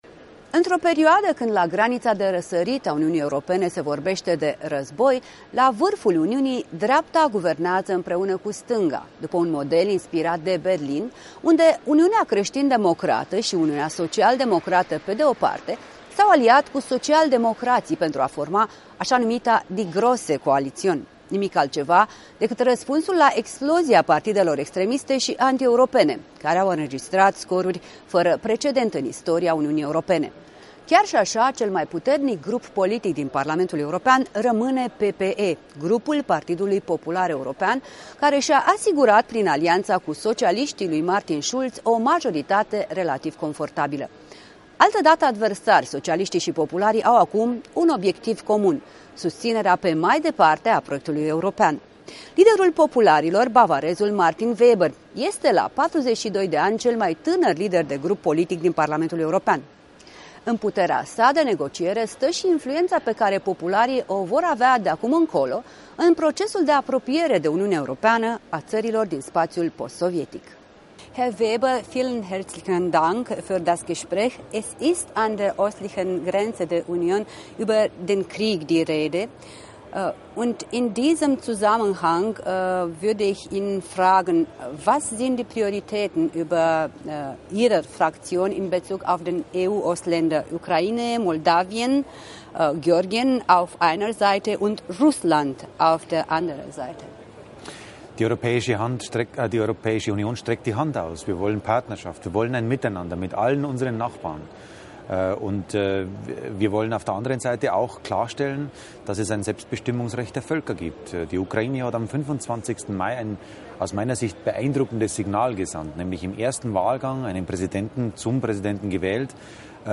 Eurodeputatul Manfred Weber răspunde întrebărilor Europei Libere